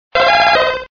Cri_0104_DP.ogg (Taille du fichier : 6 kio, type MIME : application/ogg)
Cri d'Osselait dans Pokémon Diamant et Perle.